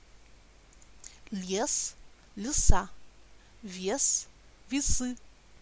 2- е et я se prononcent de la même façon quand elles ne sont pas sous l’accent: comme un e bref, presque i .
voyelle-e-inaccentuee.mp3